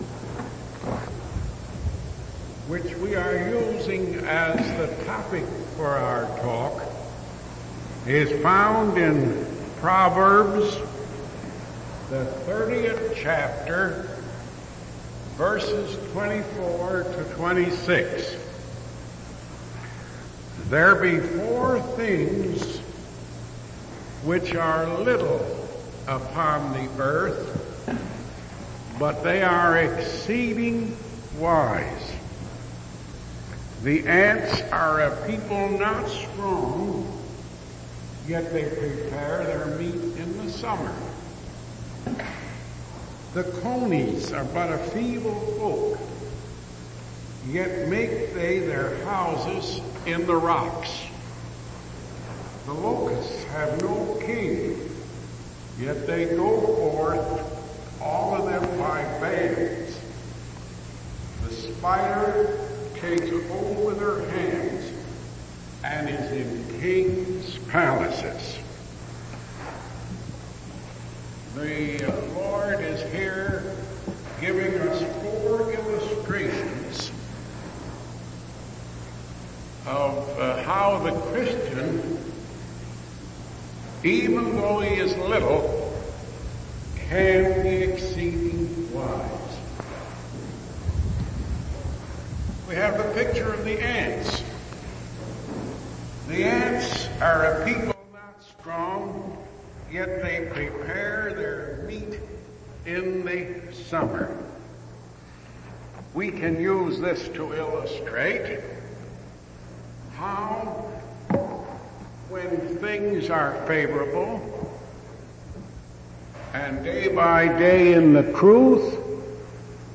From Type: "Discourse"